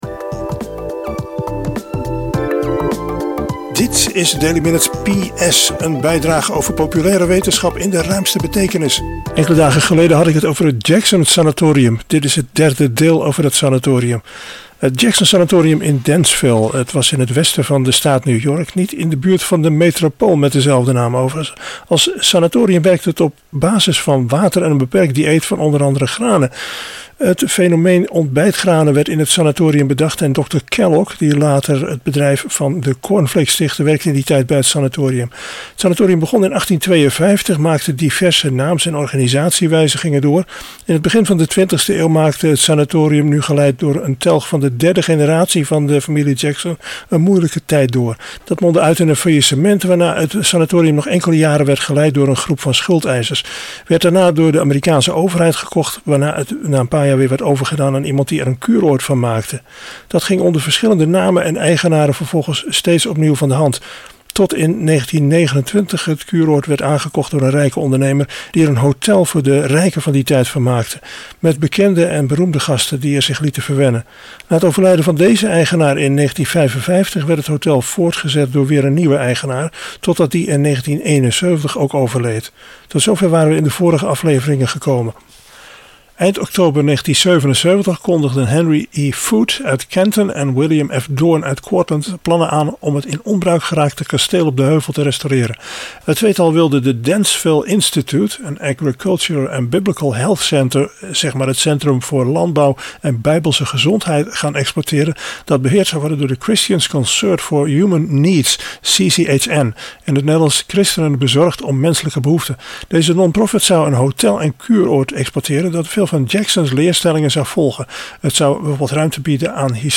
Aan het eind van de bijdrage zitten twee heel korte stukjes data: 8PSK1000 en PSKR12516C, beide 1500 Hz.